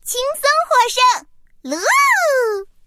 Yak3_mvpvoice.mp3